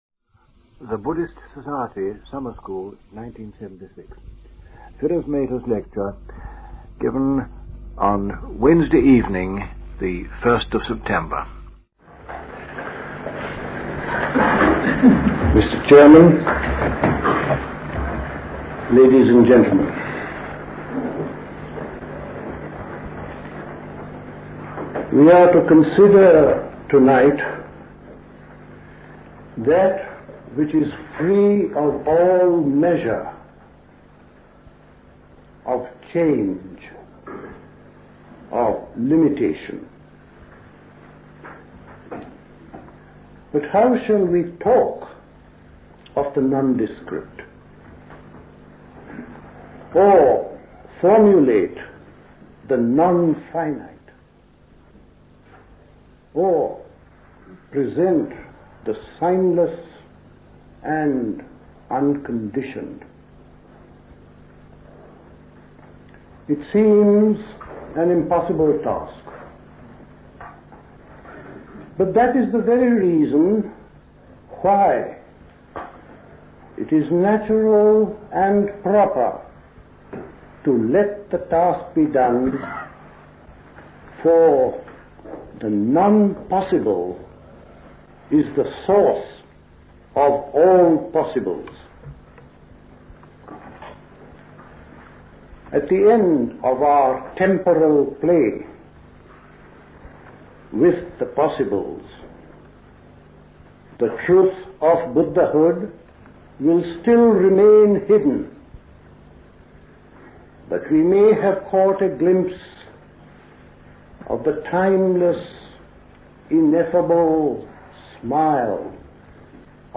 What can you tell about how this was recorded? at High Leigh Conference Centre, Hoddesdon, Hertfordshire on 1st September 1976 The Buddhist Society Summer School Talks